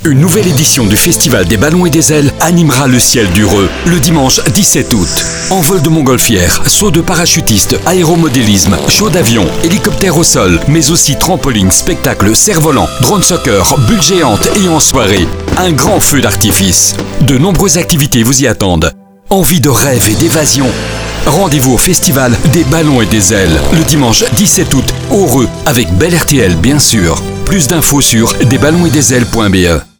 Spot radio bel RTL
Découvrez le spot radio 2025 du Festival Des Ballons et des Ailes diffusé par notre partenaire bel RTL